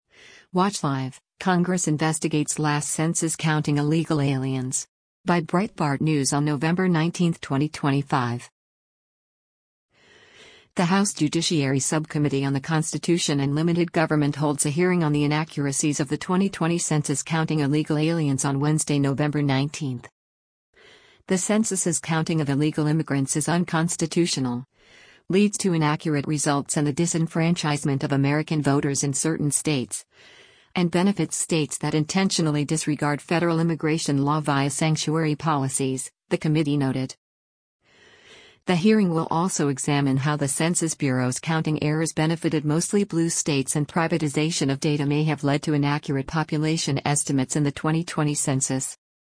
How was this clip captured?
The House Judiciary Subcommittee on the Constitution and Limited Government holds a hearing on the inaccuracies of the 2020 census counting illegal aliens on Wednesday, November 19.